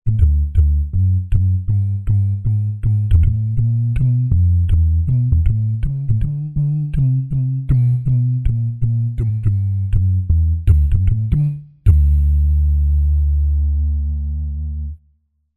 Index of /90_sSampleCDs/Spectrasonics Vocal Planet CD6 - Groove Control/Soundfinder/VP MVP Soundfinder Files/ 4. Jazz/2. Jazz Bass Multisamples